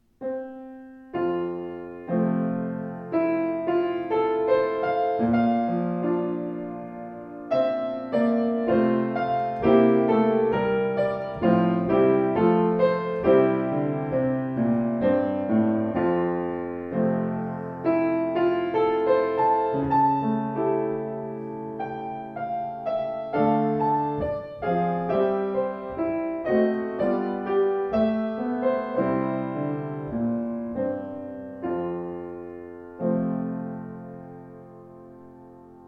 Klavier Yamaha U3 schwarz
demnächst wieder verfügbar: U3 mit vollem, konzertantem Klang in schwarz poliert